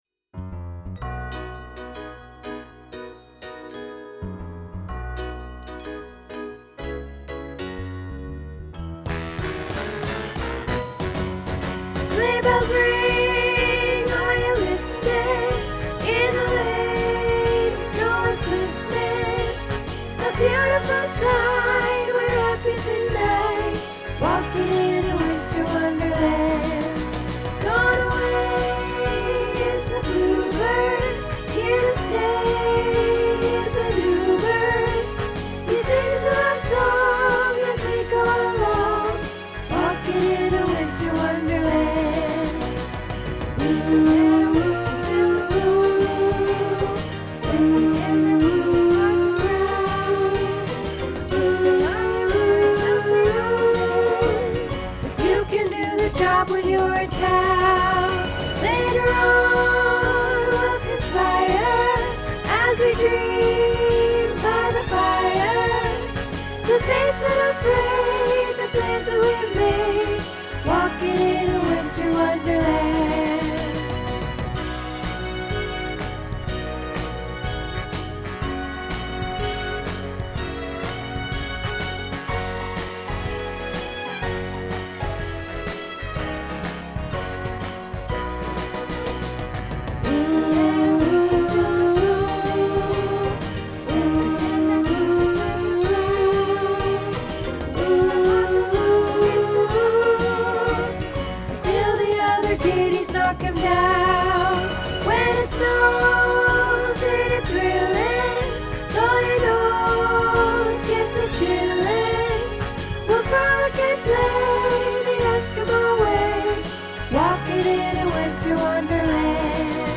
Winter Wonderland Tenor